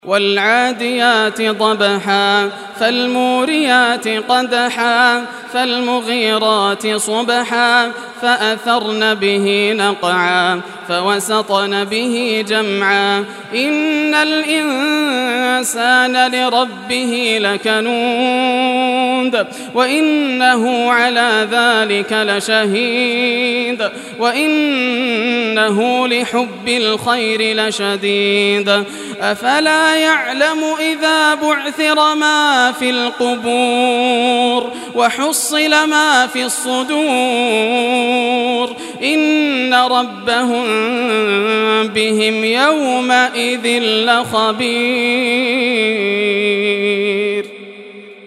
Surah Adiyat Recitation by Yasser al Dosari
Surah Adiyat, listen or play online mp3 tilawat / recitation in Arabic in the beautiful voice of Sheikh Yasser al Dosari.